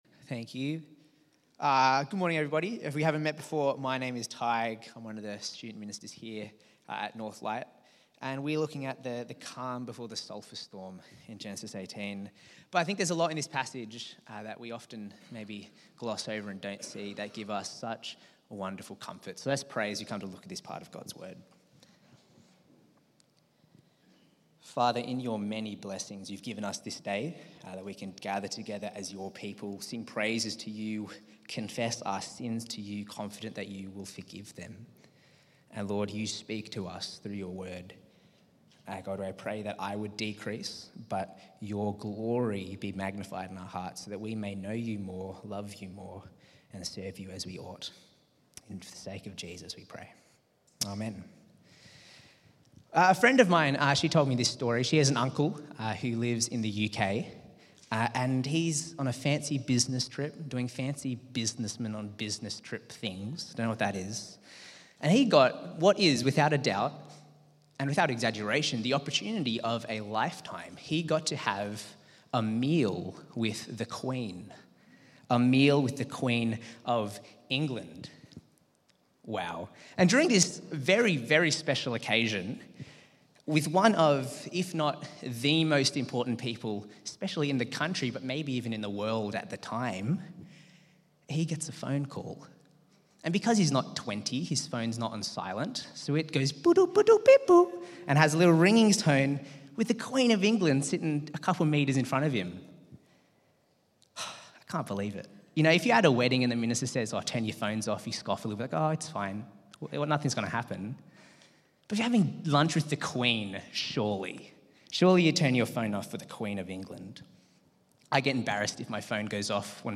Sermon on Genesis 18: Abraham’s hospitality, Sarah’s doubt, Sodom’s fate—showing God’s sure promises, justice, mercy, and security in Christ.